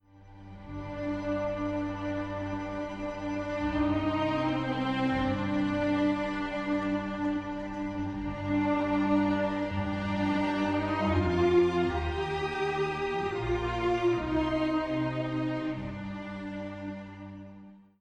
Tónica Re